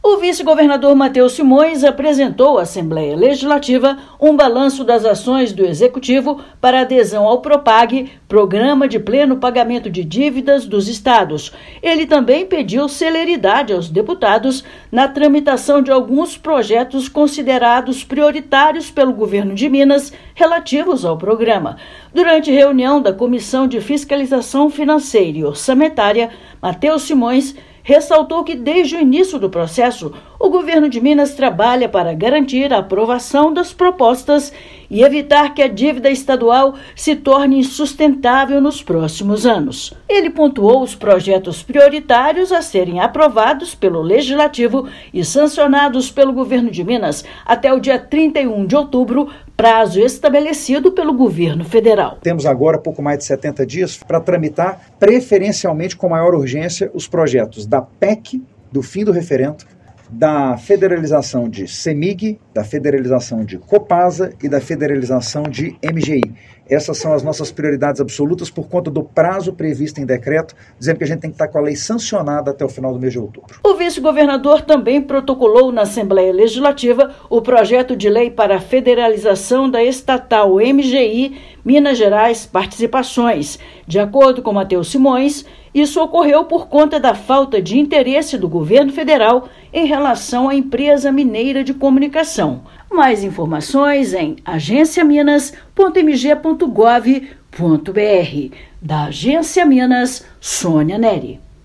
Vice-governador reforçou a importância da aprovação de projetos que autorizam a federalização de estatais como a Cemig e a Copasa. Ouça matéria de rádio.